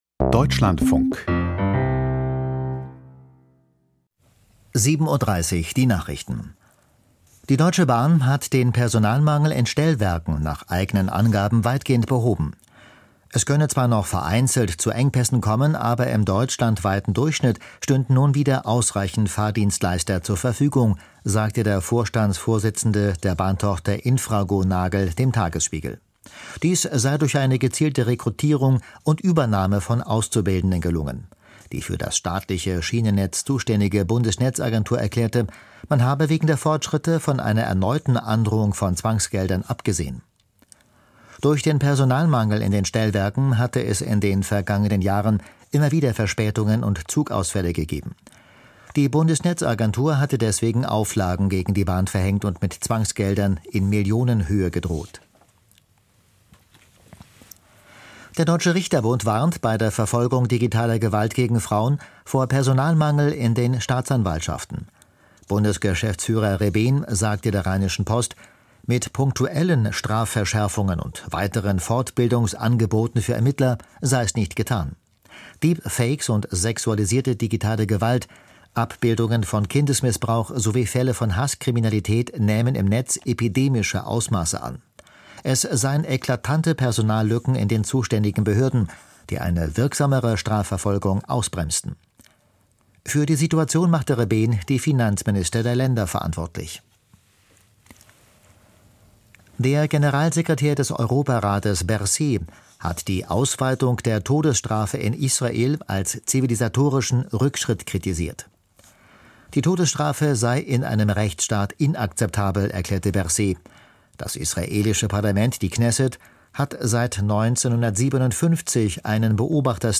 Die Nachrichten vom 31.03.2026, 07:30 Uhr
Aus der Deutschlandfunk-Nachrichtenredaktion.